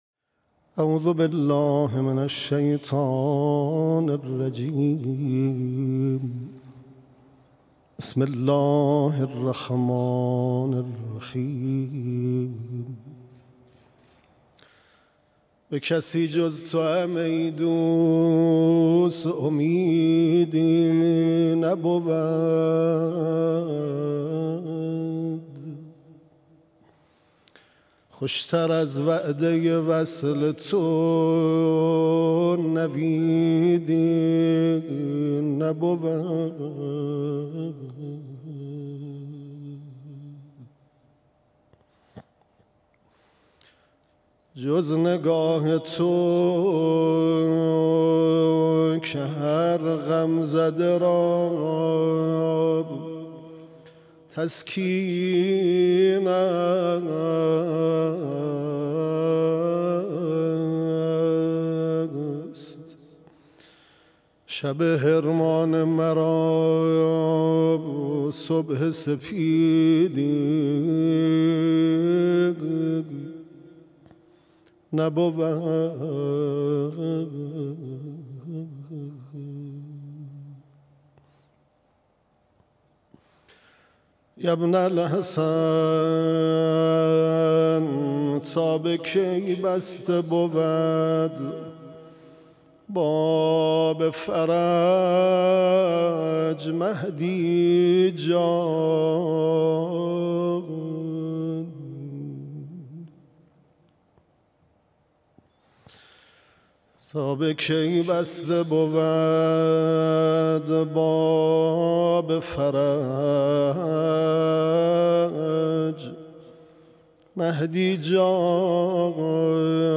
مراسم عزاداری شام شهادت حضرت فاطمه زهرا سلام‌الله‌علیها
مداحی